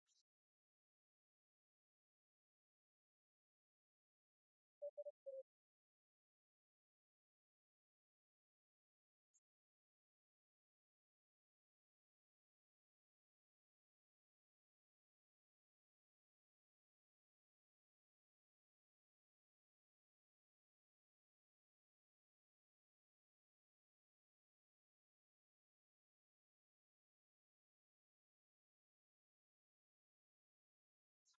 Songs, Gogo
Folk music
Field recordings
A song praising the young men in such a way as to produce national solidarity. The stamping dance with the rhythms enhanced by the sound of the leg bells is a most effective display both in sight and sound. Nindo praise song with stamping (-12.4-), Ndwala bells (-12.55-), horn and whistles.